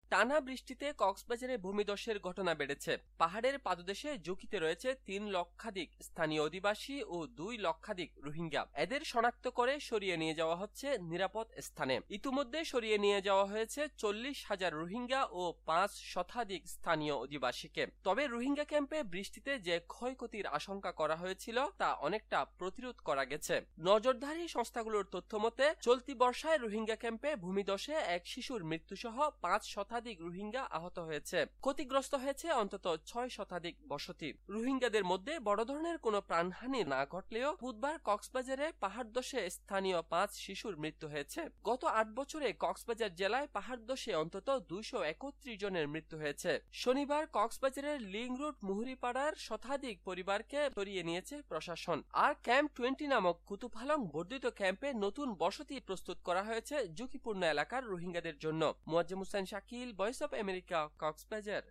কক্সবাজার থেকে